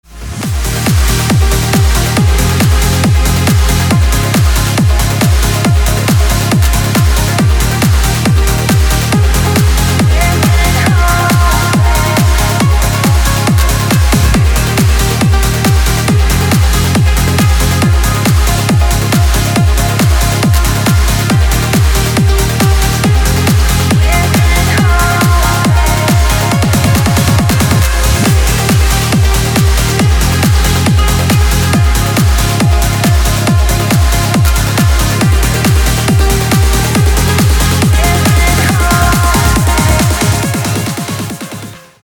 • Качество: 320, Stereo
громкие
женский вокал
женский голос
dance
электронная музыка
club
Trance